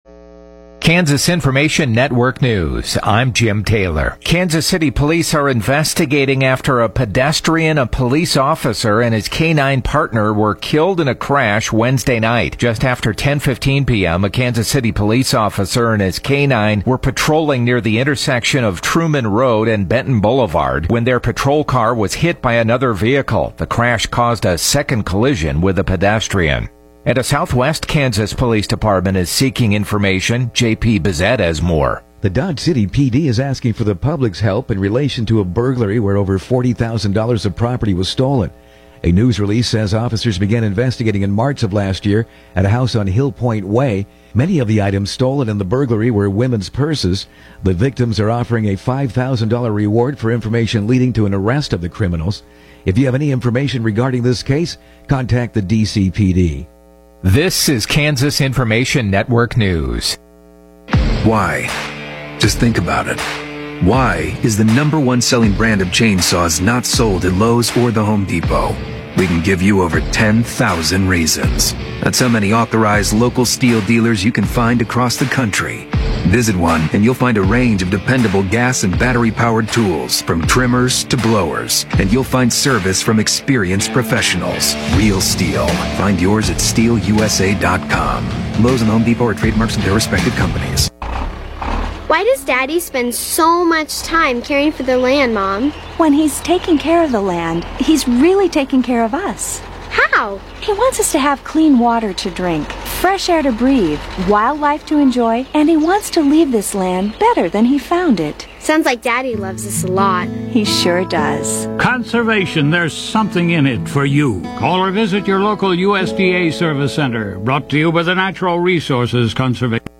The KQNK News at Noon podcast gives you local, regional and state news as well as relevant information for your farm or home as well. Broadcasts are archived daily as originally broadcast on Classic Hits 106.7 KQNK-FM.